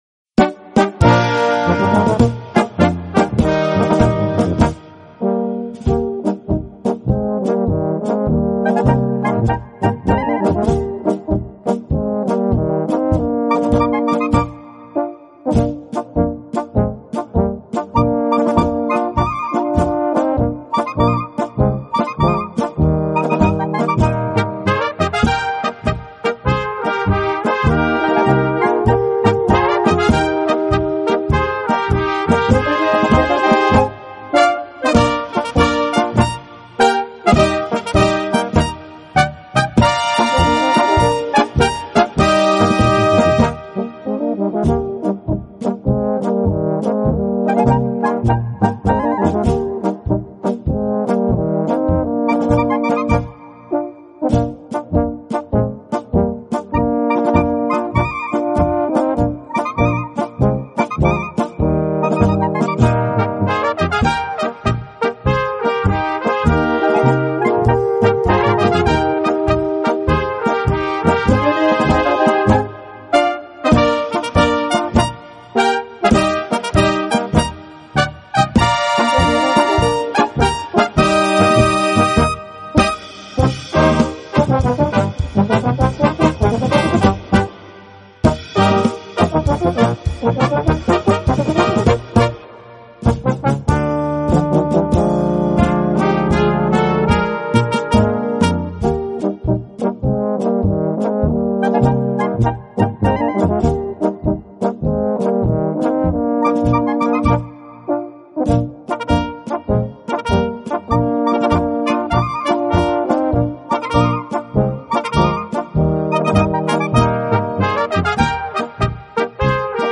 Gattung: Böhmische Polka für Blasorchester
Besetzung: Blasorchester